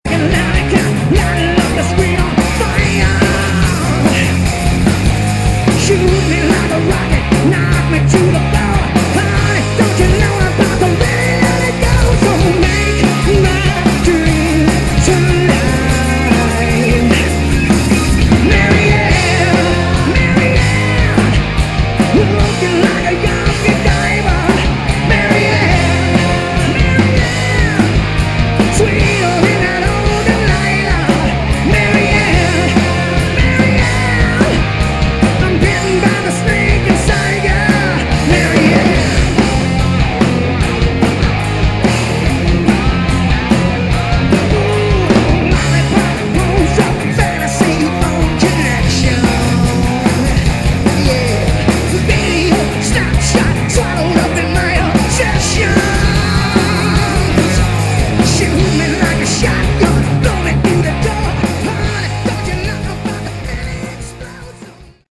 Category: Hard Rock
Lead Vocals, Acoustic Guitar
Backing Vocals
Guitar
Bass
Drums